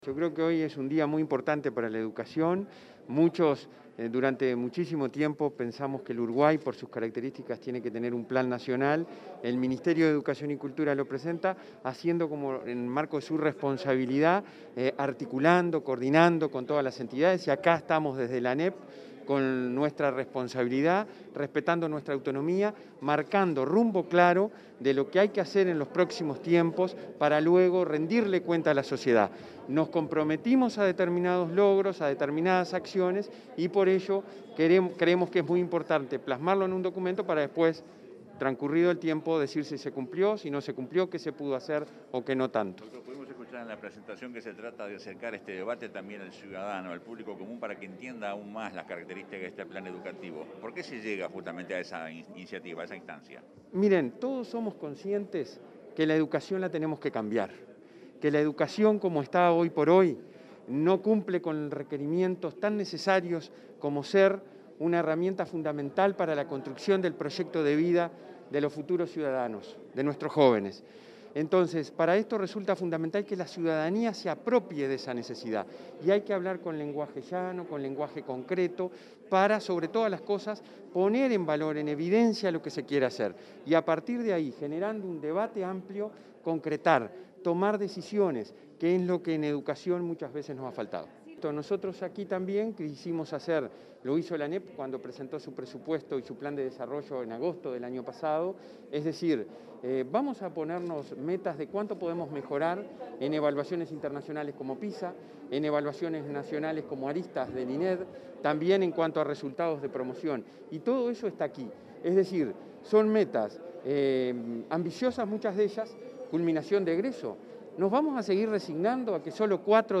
Declaraciones del presidente del Consejo Directivo Central de la ANEP, Robert Silva
Declaraciones del presidente del Consejo Directivo Central de la ANEP, Robert Silva 05/08/2021 Compartir Facebook X Copiar enlace WhatsApp LinkedIn Este jueves 5, en la sala Vaz Ferreira, el presidente del Consejo Directivo Central (Codicen) de la Administración Nacional de Educación Pública (ANEP), Robert Silva, brindó declaraciones a la prensa, luego de presentar a la ciudadanía el Plan de Política Educativa Nacional 2020-2025.